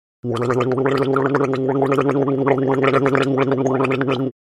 Звуки полоскания горла